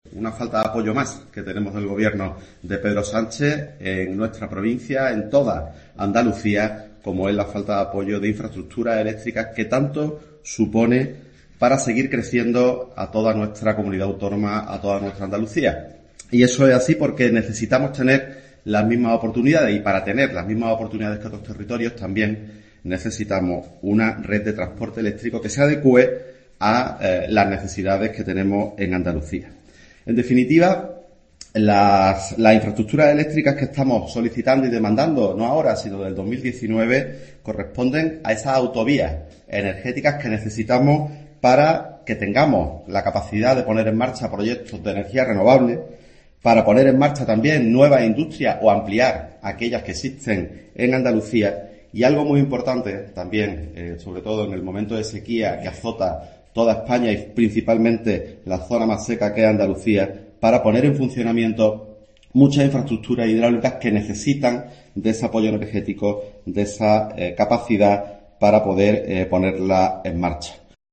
En una rueda de prensa en Córdoba, junto al presidente del PP cordobés, Adolfo Molina, el número dos del PP autonómico ha declarado que "Andalucía no para de crecer y desde el PP se va a seguir impulsando su desarrollo, por muy altos que sean los muros que levante Sánchez".